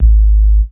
puredata/resources/808_drum_kit/kicks/808-Kicks39.wav at 2f62dcfa9559c2c932b49ec97680dbd6f42c9a5b
808-Kicks39.wav